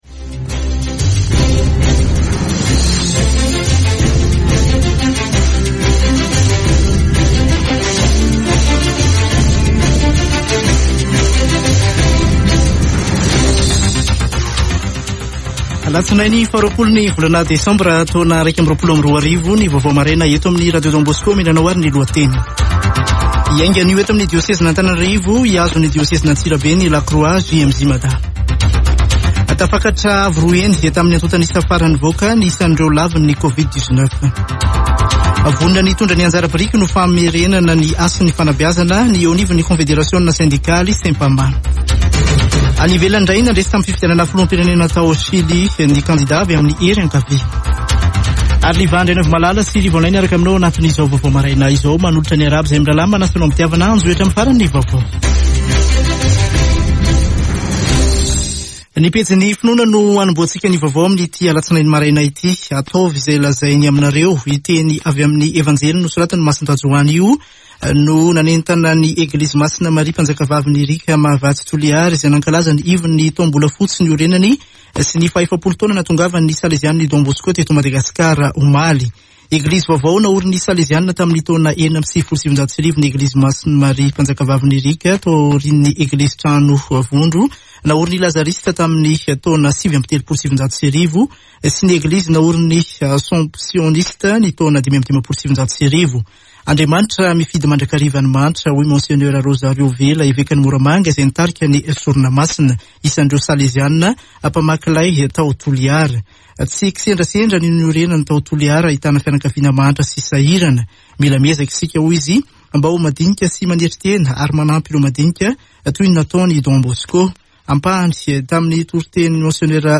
[Vaovao maraina] Alatsinainy 20 desambra 2021